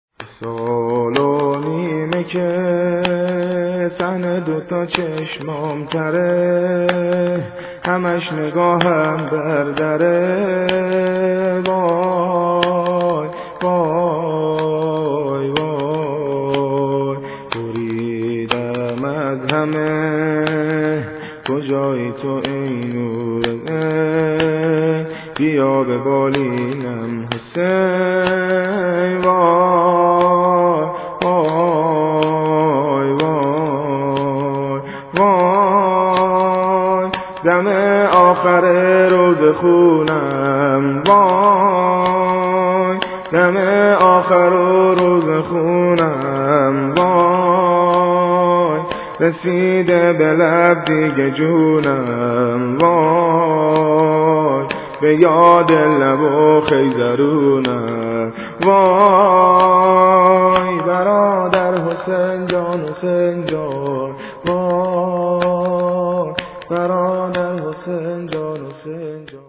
شور ، زمینه